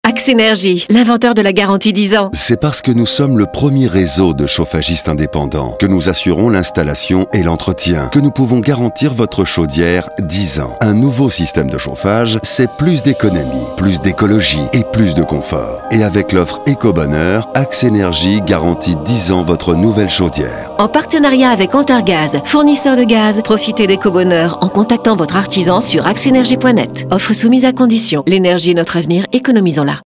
Spot radio Axenergie
Axenergie-RMCRTL.mp3